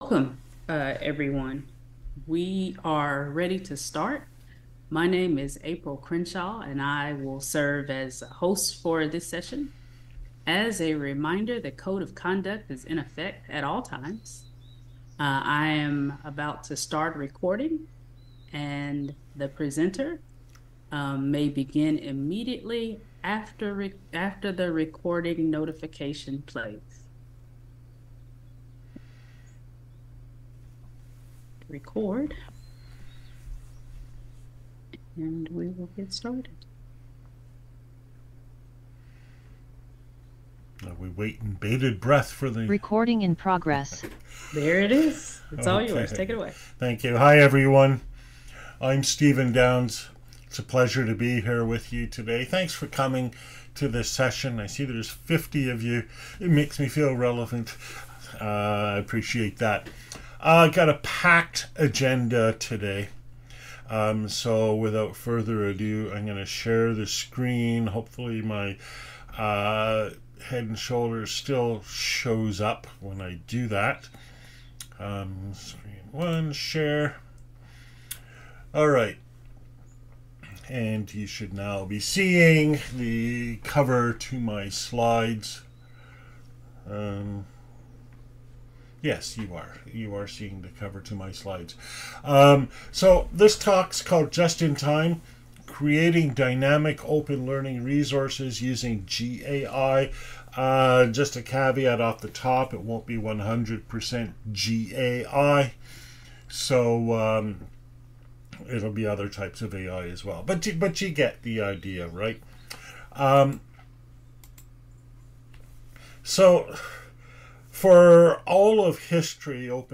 The GAI could address each of the issues with static online learning resources (SOLR) by addressing it based on the learning need at the time of that need, as described by the specific prompt and the context of application. This presentation will provide an overview of the technology required to support such an approach.
(Old style) [ Slides ] [ PDF ] [ Audio ] [ Video ] (New Style) [ PDF ] [ Audio ] [] Open Education 2024, Providence, RI, via Zoom, Oct 08, 2024.